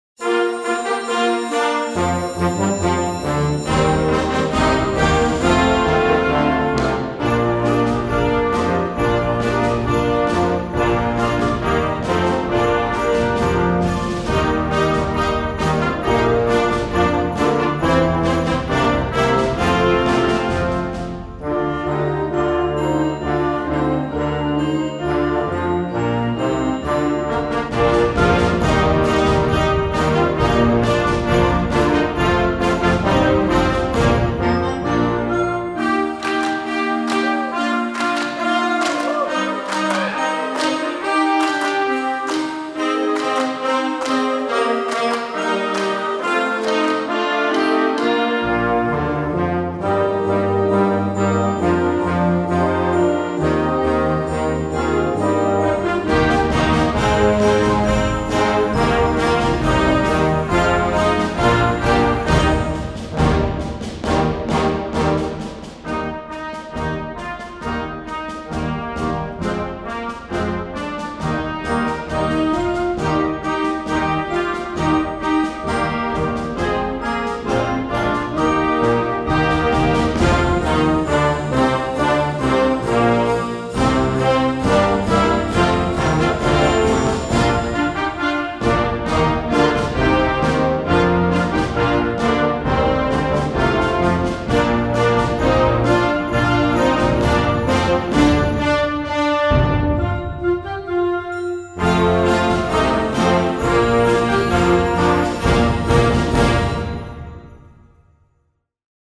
Gattung: Weihnachtslieder für Jugendblasorchester
Besetzung: Blasorchester